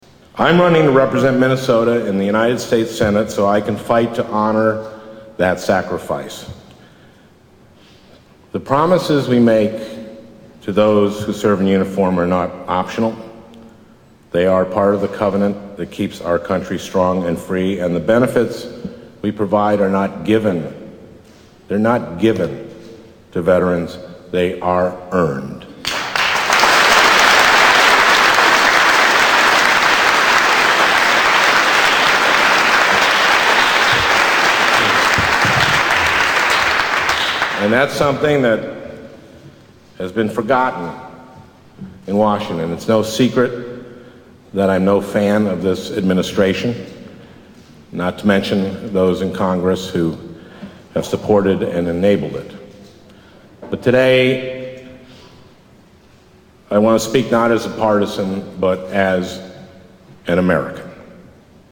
Veterans for Franken Rally